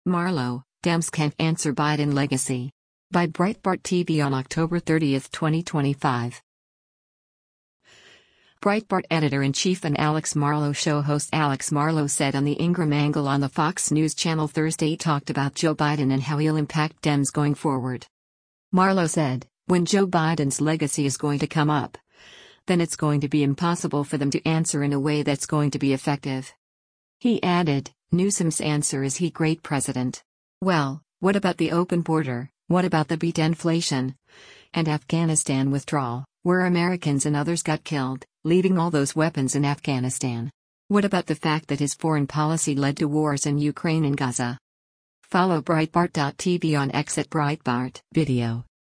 Breitbart Editor-in-Chief and “Alex Marlow Show” host Alex Marlow said on the “Ingraham Angle” on the Fox News Channel Thursday talked about Joe Biden and how he’ll impact Dems going forward.